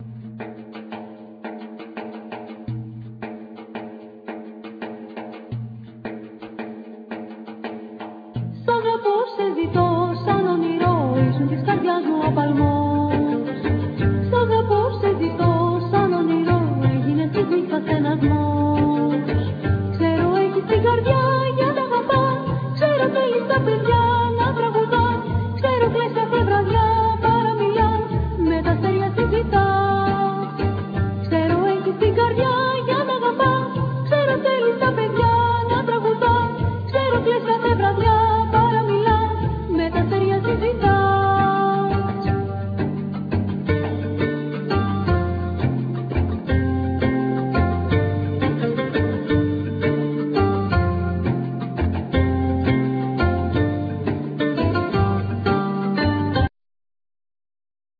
Ney
Oud,Vocals
Kalimba
Bass,Synthsizer,Percussions,Mandola,12 string guitar
Violin